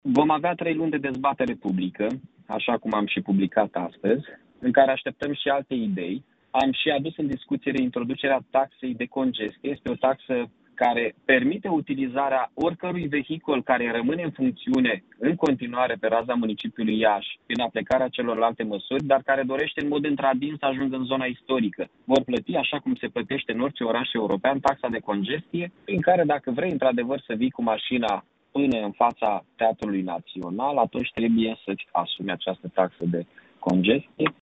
Primarul Iaşului, Mihai Chirica, a spus că proiectele vor intra în dezbaterea Consiliului Local peste 3 luni: